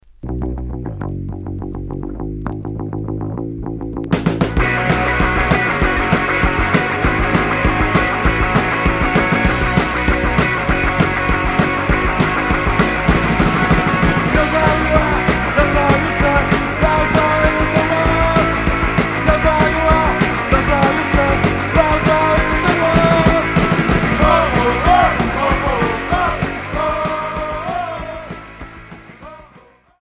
Punk rock Unique 45t retour à l'accueil